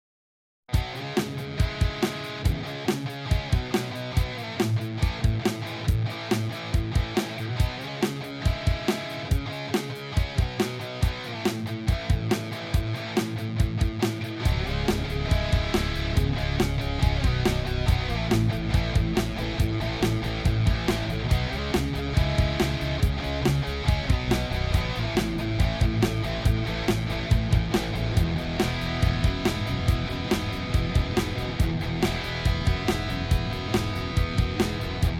Dávám sem vzorek bez BB bicích (jen pattern z B3) a celý nápad s bubnama od Buddyho.